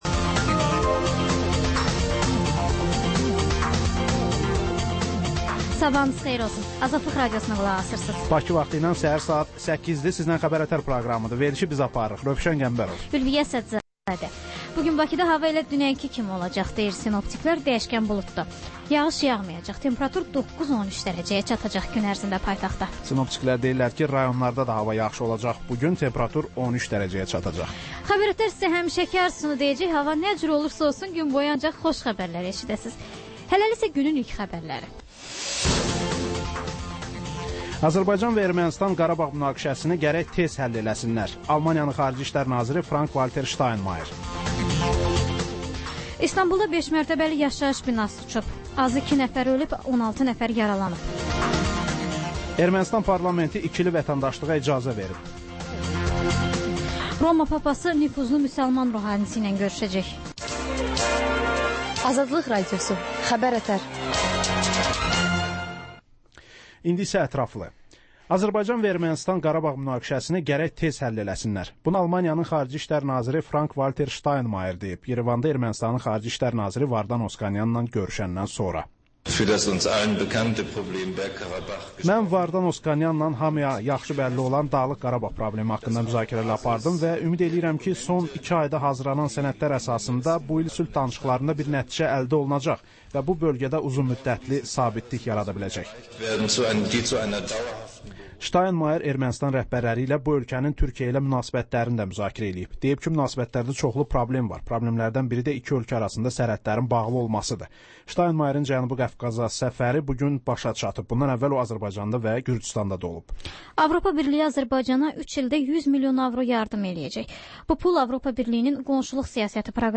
Xəbərlər
Xəbərlər, reportajlar, müsahibələr.